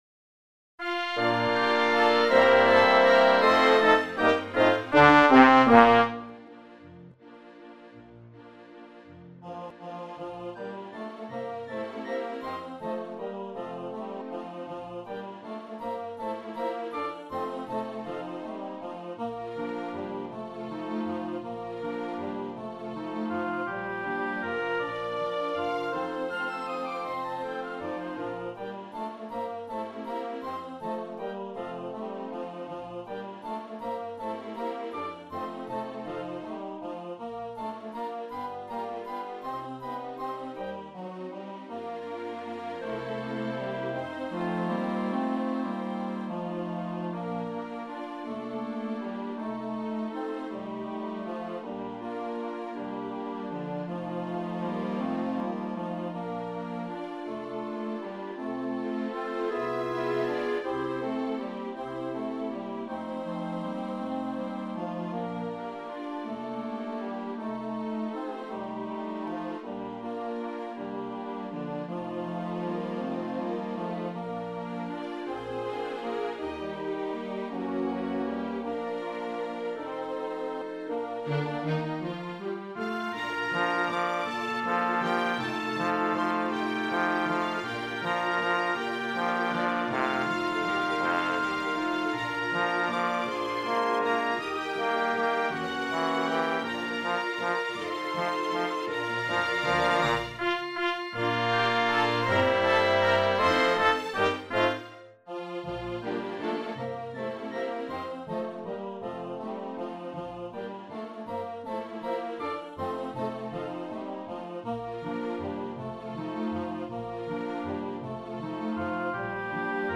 Con, Kid and Chorus